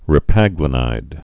(rĭ-păglə-nīd)